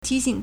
提醒 tíxǐng
ti2xing3.mp3